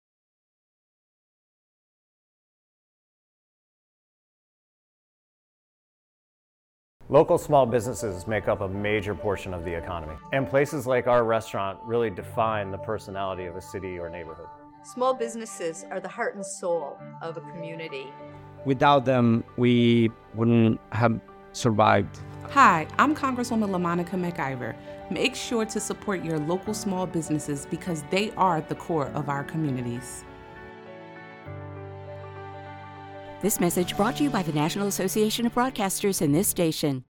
Representatives Herb Conaway (NJ-03), LaMonica McIver (NJ-10), and Rob Menendez (NJ-08) recorded radio and television PSAs covering a multitude of important subjects.